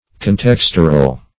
Search Result for " contextural" : The Collaborative International Dictionary of English v.0.48: Contextural \Con*tex"tur*al\ (?; 135), a. Pertaining to contexture or arrangement of parts; producing contexture; interwoven.